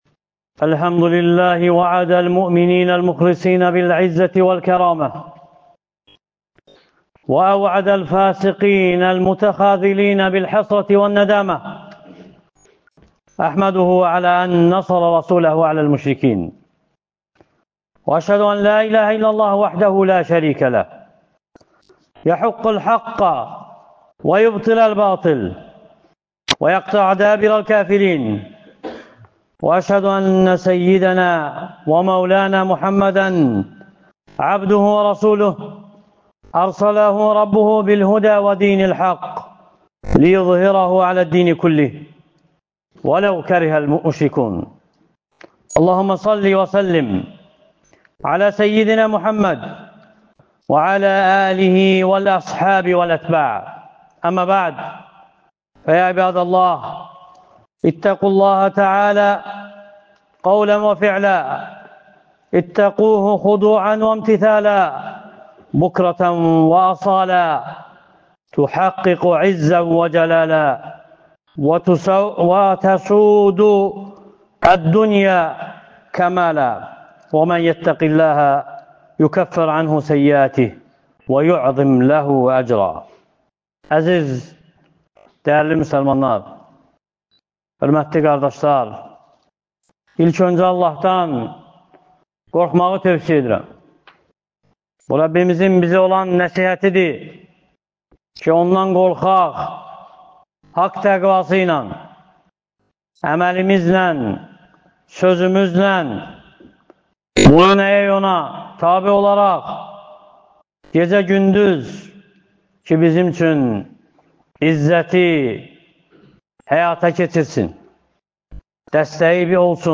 Ömər ibn Xəttabın (Allah ondan razı olsun) fəziləti (Cümə xütbəsi — 27.12.2024) | Əbu Bəkr məscidi